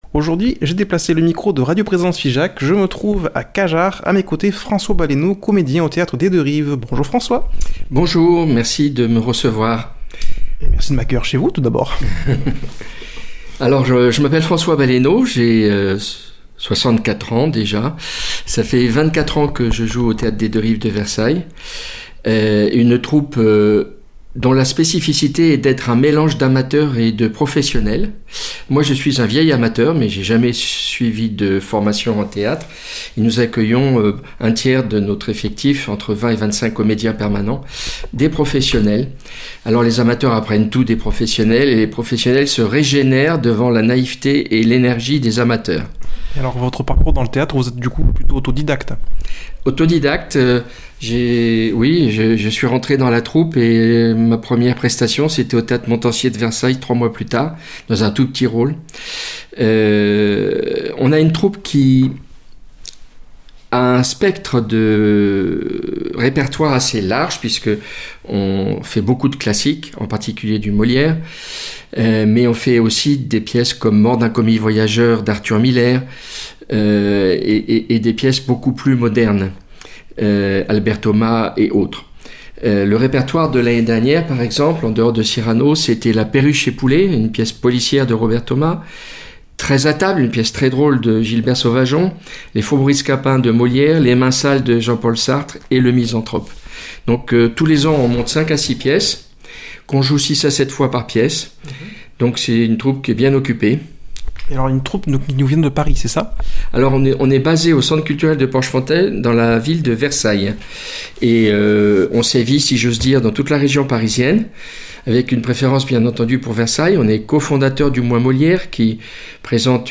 a déplacé le micro de Radio Présence Figeac à Cajarc.